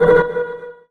sci-fi_alarm_warning_loop_01.wav